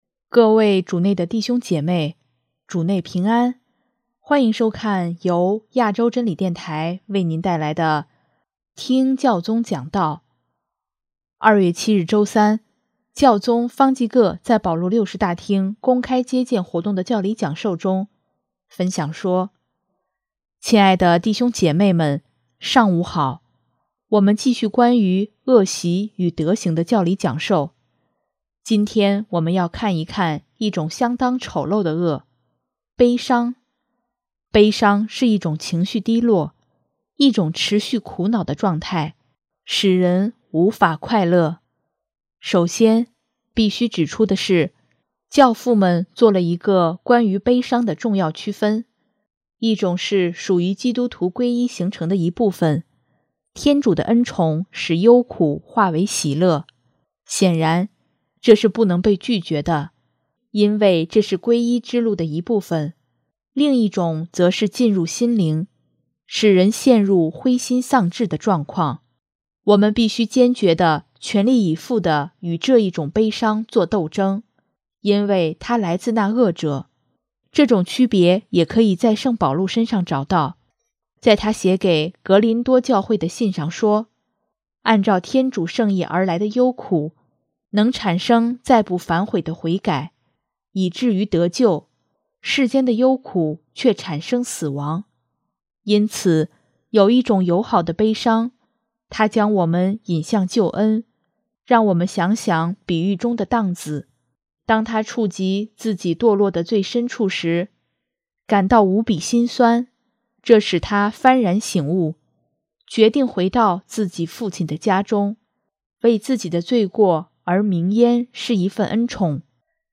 2月7日周三，教宗方济各在保禄六世大厅公开接见活动的教理讲授中，分享说：